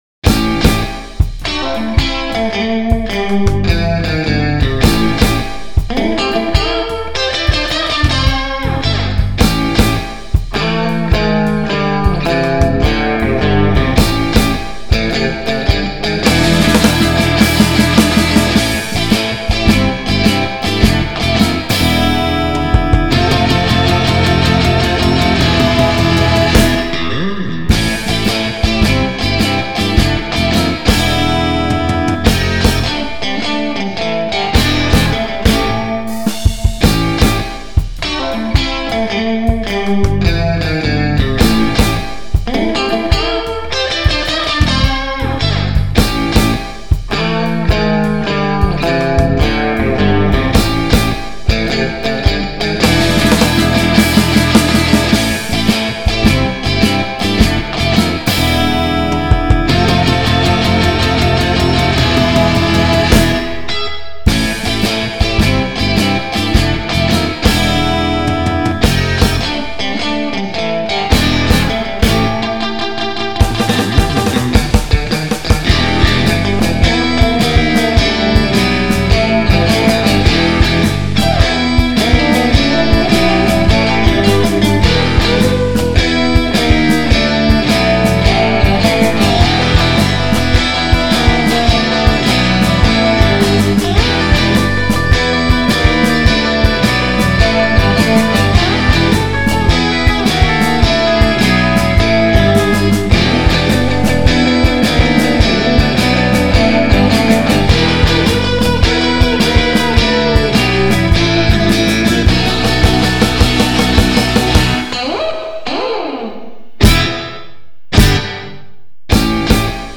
Looking for any comments/advice on my sound/mixing/etc. I have been recording and creating instrumental music on Reaper for about two years in my free time. Guitar is jacked in on a line6 box, everything else is NI.